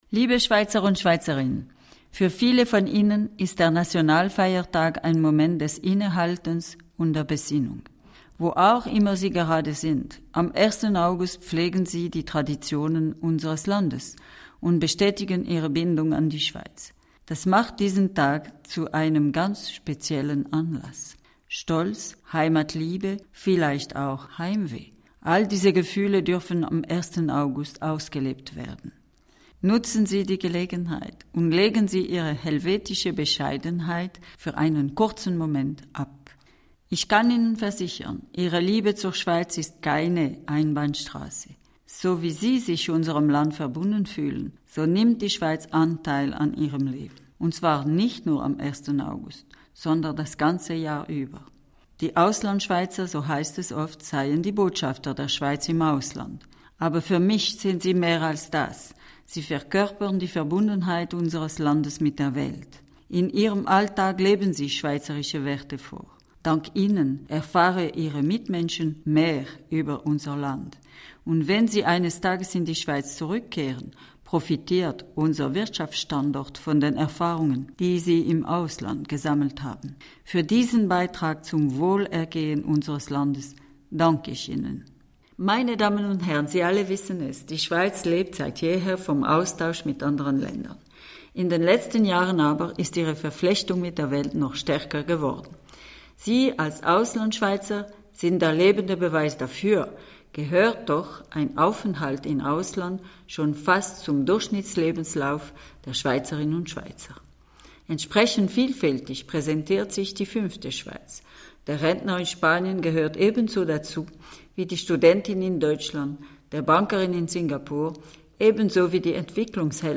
Grussbotschaft von Bundespräsidentin Micheline Calmy-Rey an die Adresse der AuslandschweizerInnen zum Schweizerischen Nationalfeiertag.